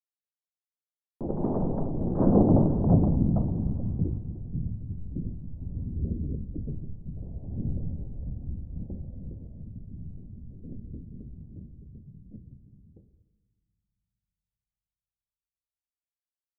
Thunder 03.wav